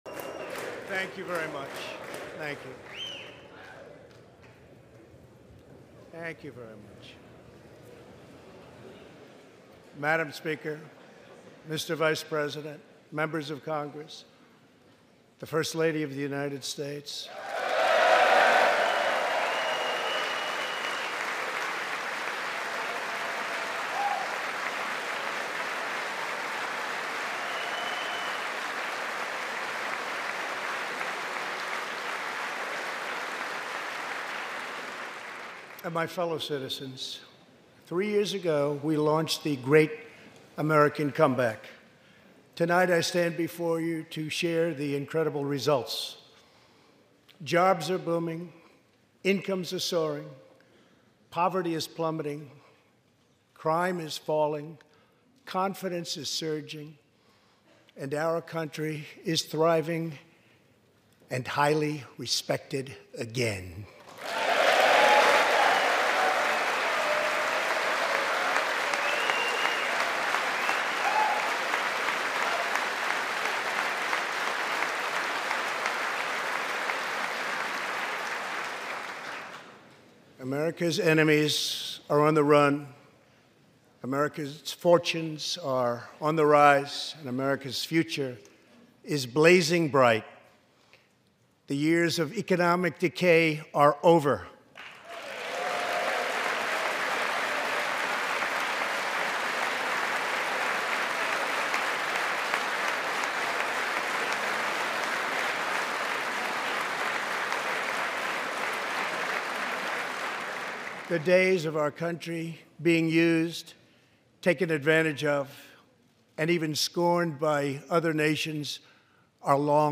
February 4, 2020: State of the Union Address